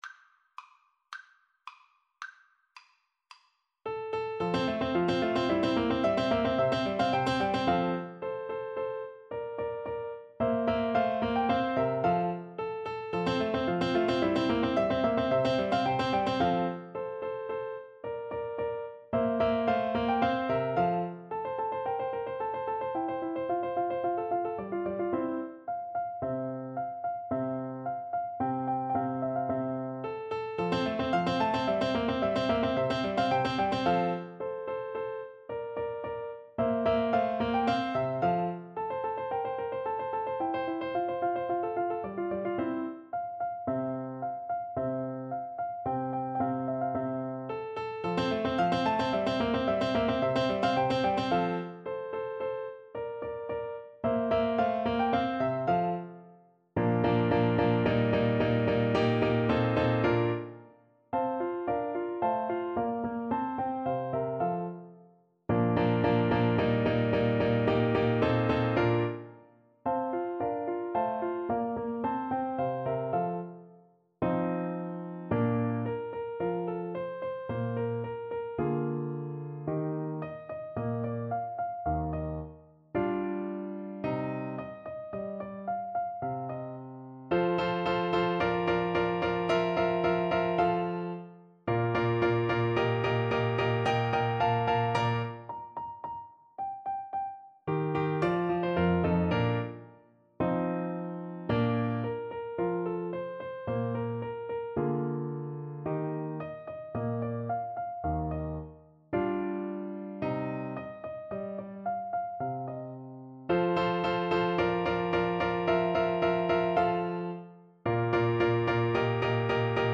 Vivace assai =110 (View more music marked Vivace)
Classical (View more Classical Trumpet Music)